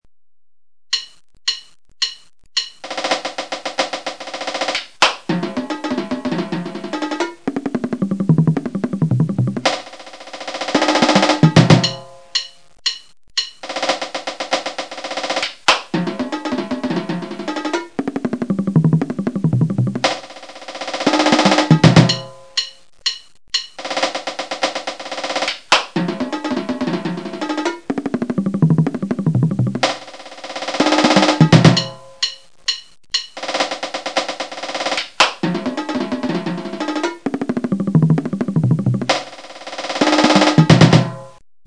MARCHPERC3.SF2 - The Marching Percussion SoundFont
(Additional voices added by me from another soundfont - that's why they may not sound like they "belong" with the others)
Snare77Kevlar head, of course
marchperc3.mp3